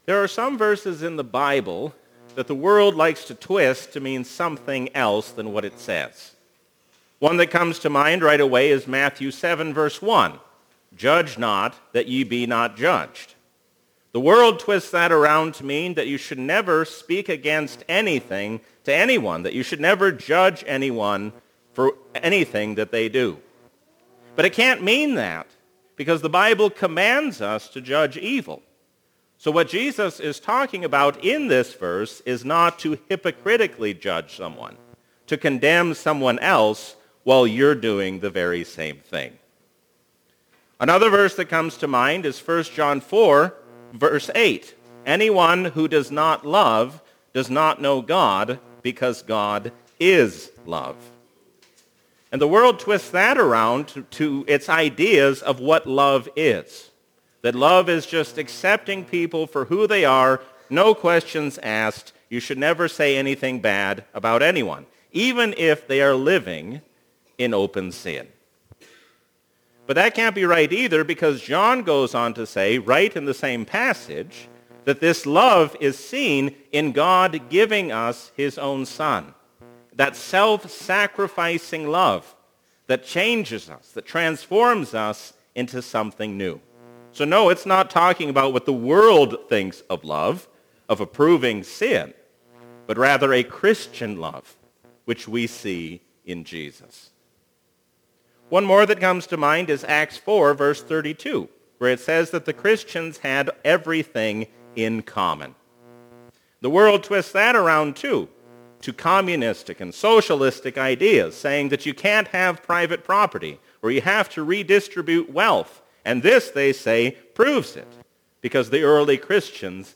A sermon from the season "Christmas 2021." We are no longer spiritually children, because our coming of age happened in the birth of Jesus.